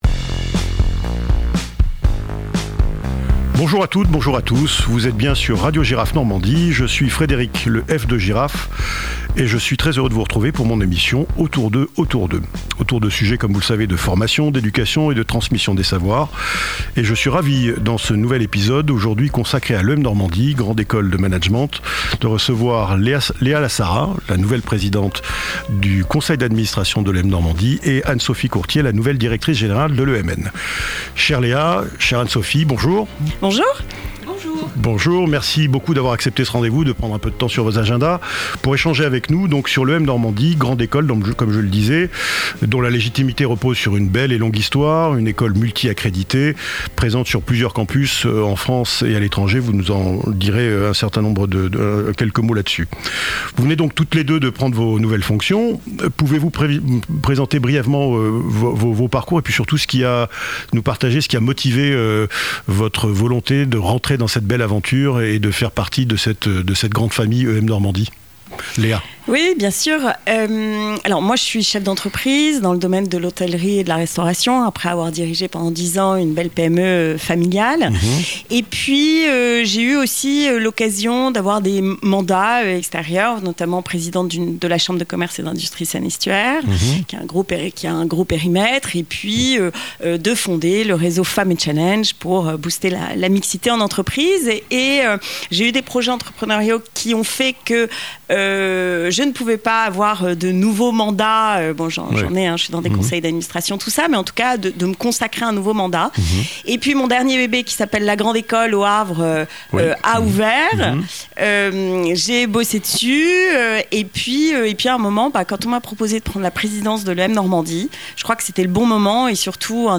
Dans la nouvelle interview de Radio Girafe Normandie